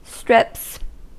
Ääntäminen
Ääntäminen US Tuntematon aksentti: IPA : /strɪps/ Haettu sana löytyi näillä lähdekielillä: englanti Käännöksiä ei löytynyt valitulle kohdekielelle. Strips on sanan strip monikko.